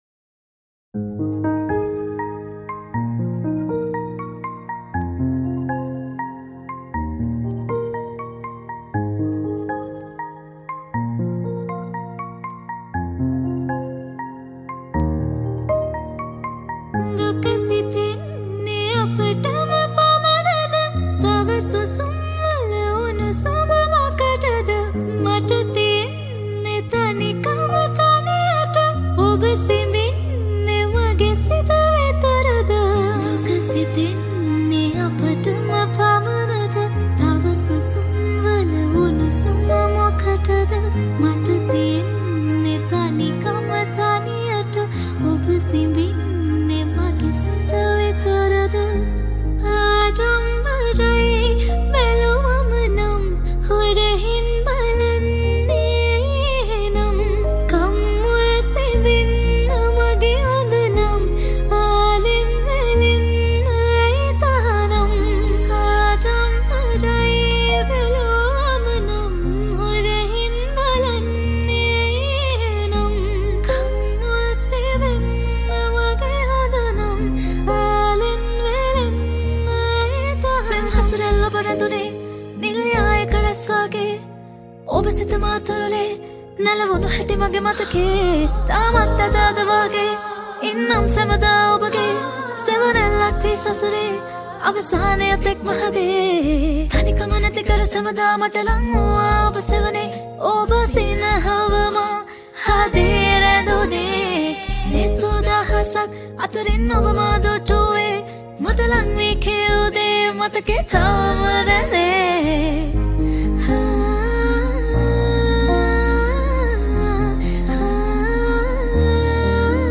Original Song By Vocals